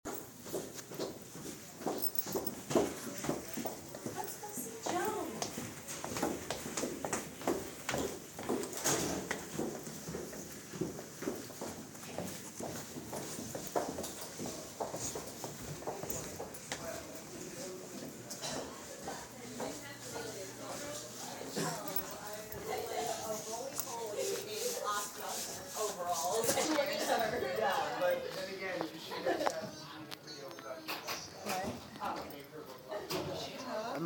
Field Recording 7
Location: Second to first floor of Emily Lowe
Sounds: Footsteps, music, talking.